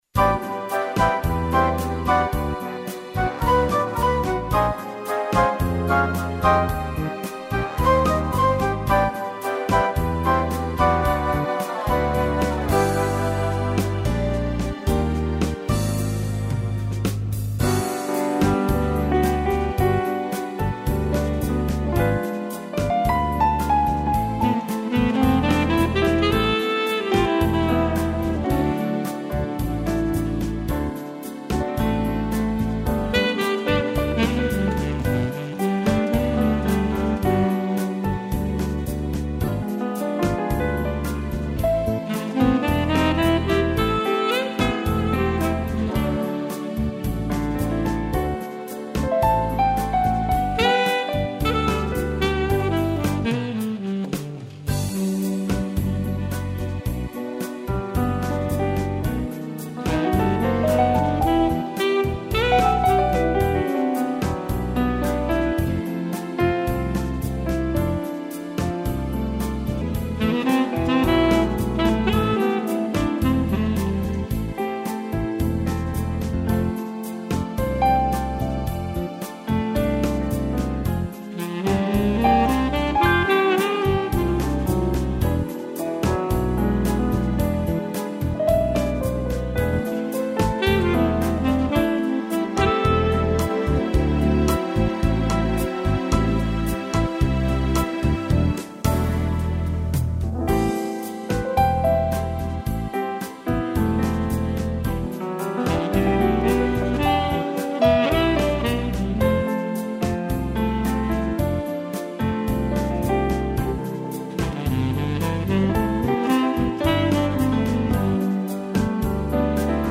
piano
sax